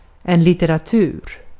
Ääntäminen
Ääntäminen Tuntematon aksentti: IPA: [ˌlɪt.te.raˈtʉː r] Haettu sana löytyi näillä lähdekielillä: ruotsi Käännös Ääninäyte Substantiivit 1. literature Artikkeli: en .